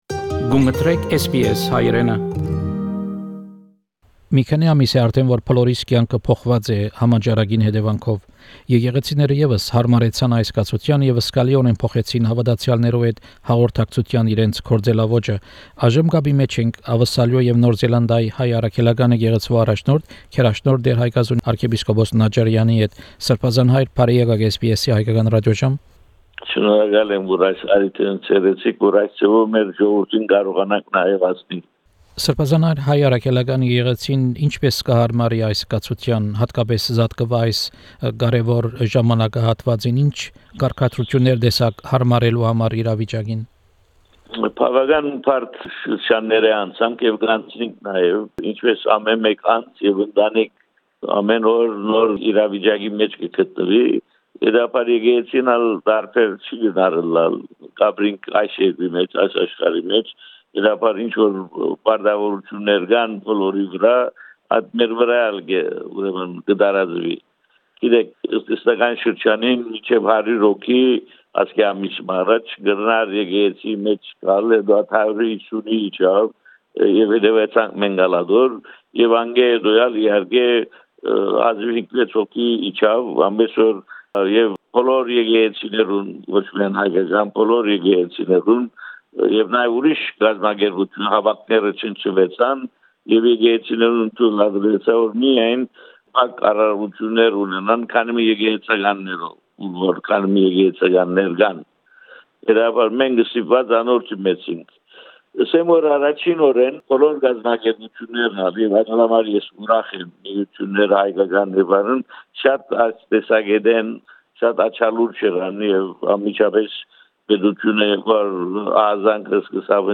Interview with His Eminence Archbishop Haigazoun Najarian, Primate of the Armenian Apostolic Church in Australia and New Zealand. The main topic of the interview is COVID-19 and how it affected church services and the community.